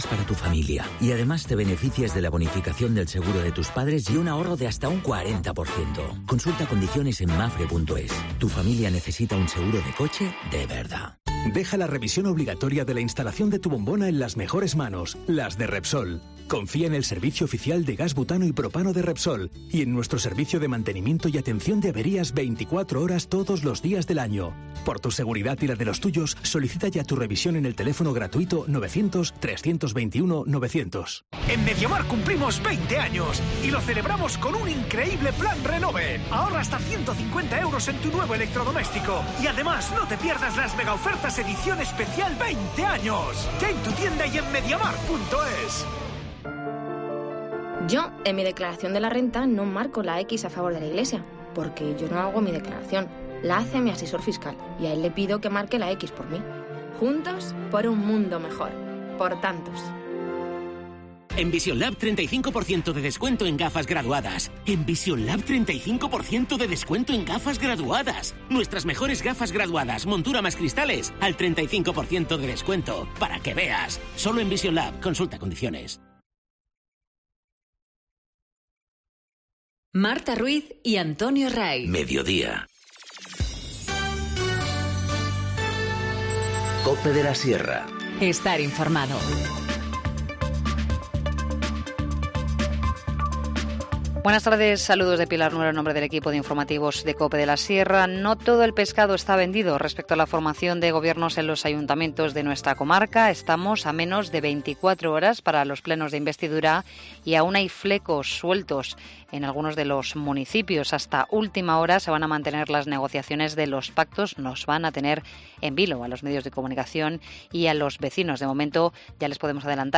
Informativo Mediodía 14 junio 14:20h